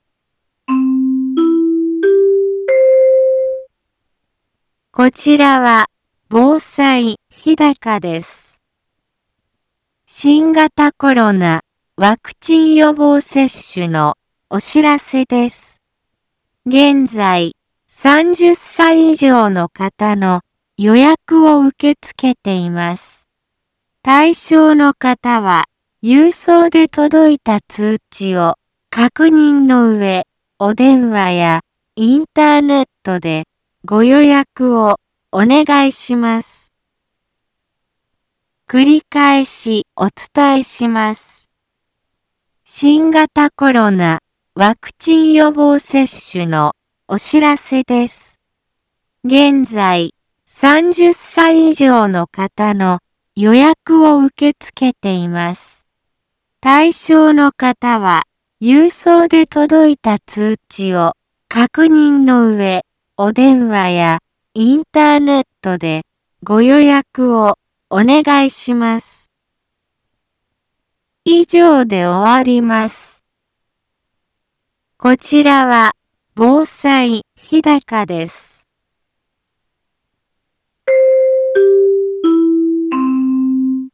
一般放送情報
BO-SAI navi Back Home 一般放送情報 音声放送 再生 一般放送情報 登録日時：2021-08-10 10:03:00 タイトル：新型コロナワクチン予防接種のお知らせ インフォメーション：こちらは防災日高です。 新型コロナワクチン予防接種のお知らせです。